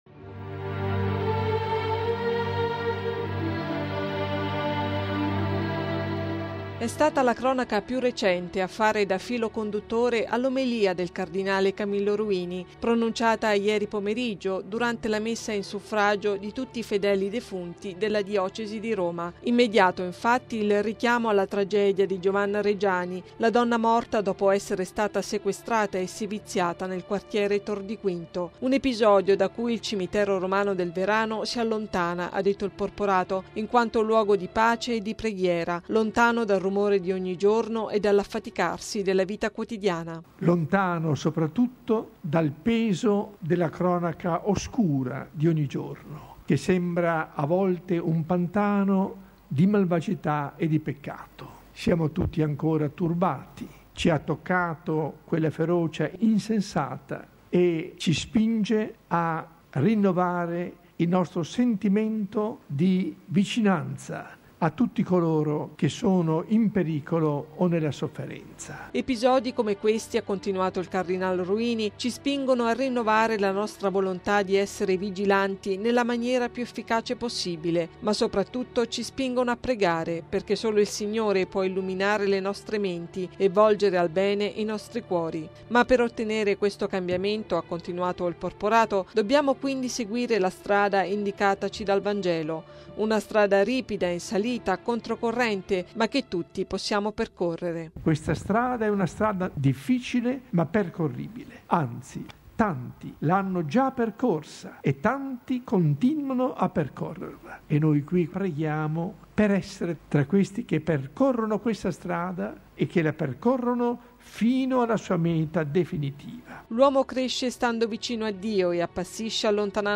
Il cardinale Ruini presiede la Messa per i defunti al Cimitero del Verano
(musica)